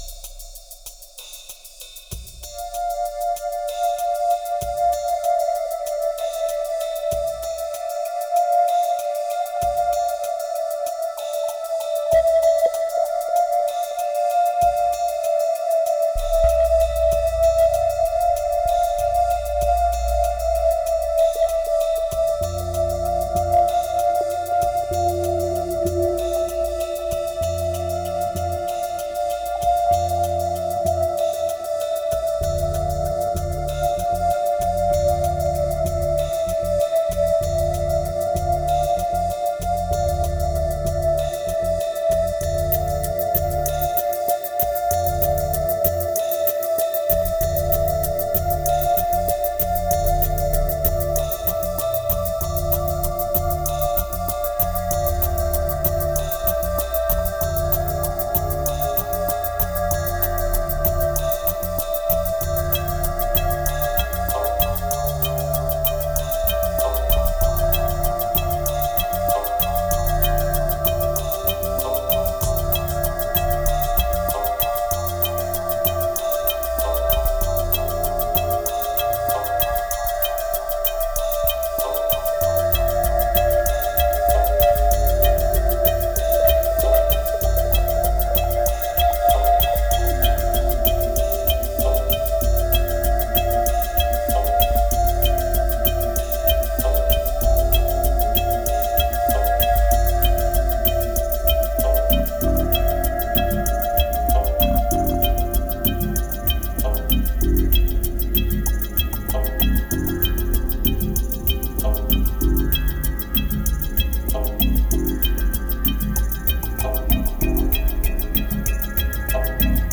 Ambient maybe ?
2347📈 - 69%🤔 - 96BPM🔊 - 2013-08-11📅 - 286🌟